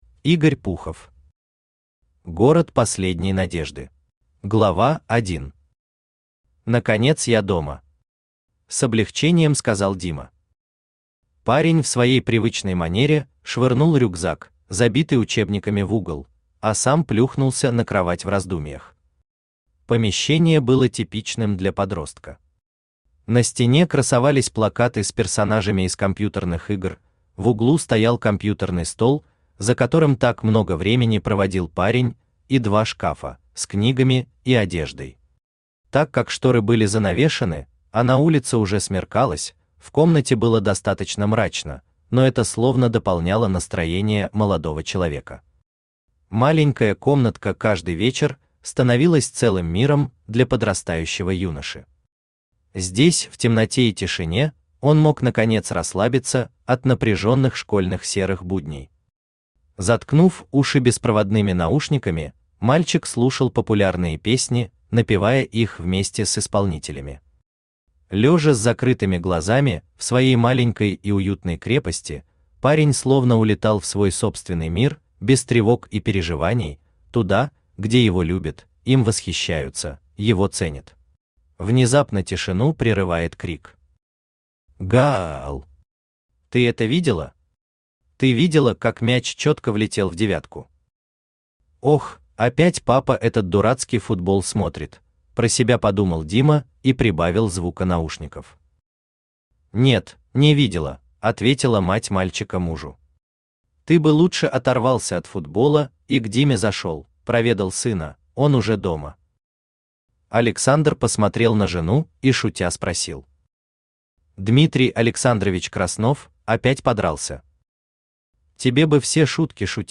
Аудиокнига Город последней надежды | Библиотека аудиокниг
Aудиокнига Город последней надежды Автор Игорь Алексеевич Пухов Читает аудиокнигу Авточтец ЛитРес.